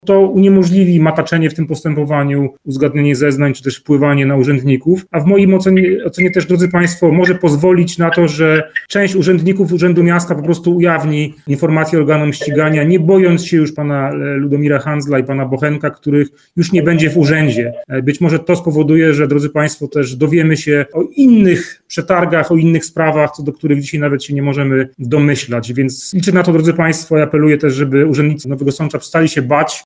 Arkadiusz Mularczyk apelował podczas konferencji prasowej do pracowników Urzędu Miasta Nowego Sącza, by przestali się bać.